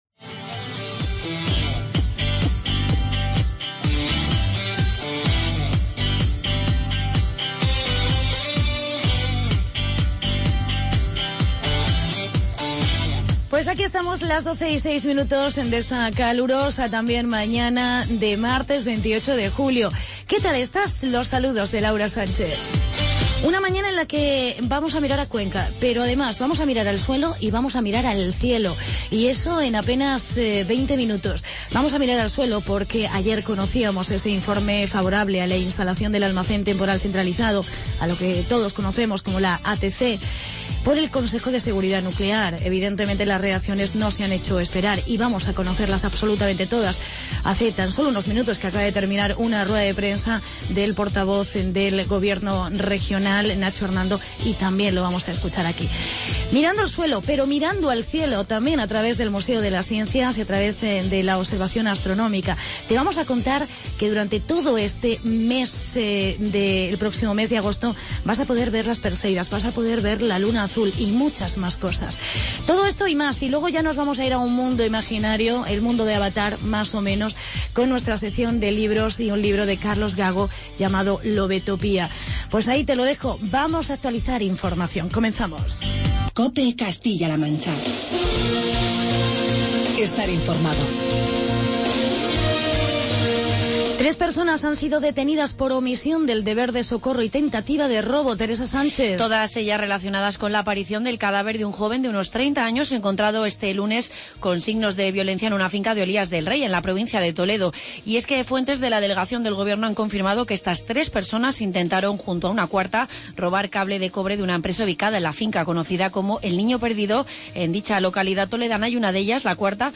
Reacciones ATC. Entrevista